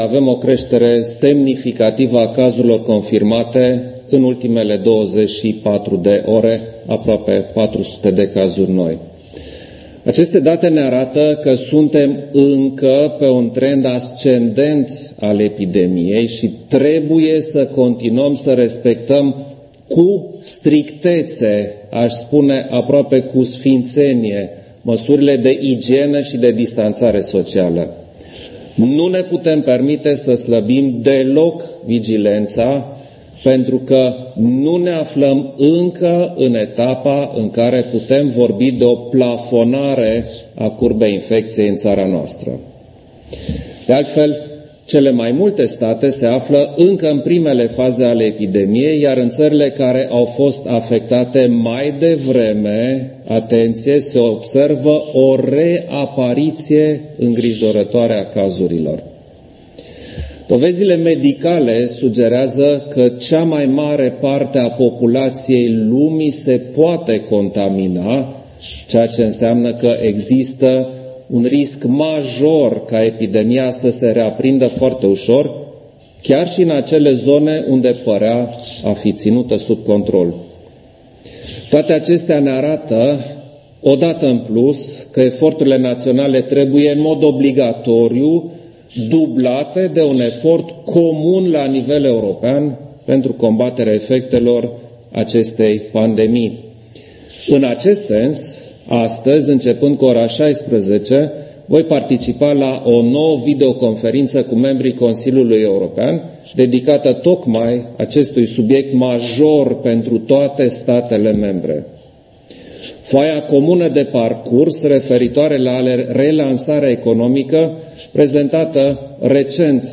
Klaus Iohannis a susținut o declarație de presă de la ora 14, în care a anunțat că, la nivel european, a fost mobilizată o sumă de 1,5 miliarde de euro pentru România, din actualul buget multianual al UE.